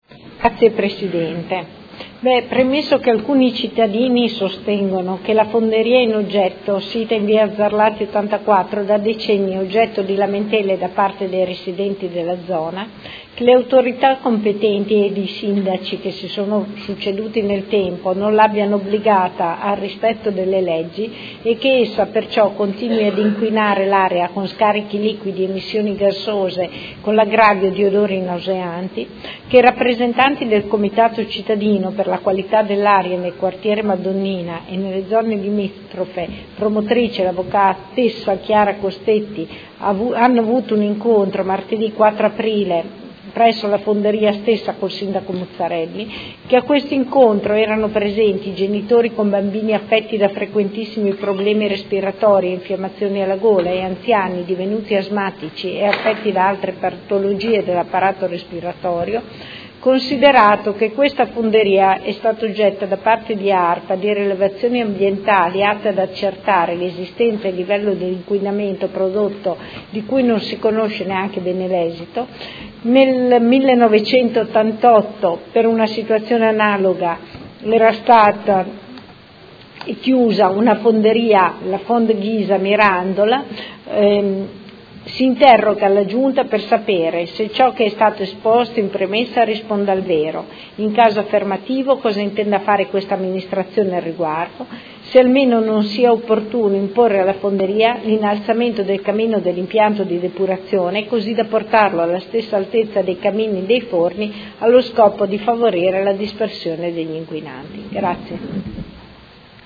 Seduta del 18/05/2017. Interrogazione della Consigliera Santoro del Gruppo IDEA – Popolo e Libertà avente per oggetto: Fonderie Cooperative di Modena S.C. AR.L. nel Quartiere Madonnina, inquinamento dell’ambiente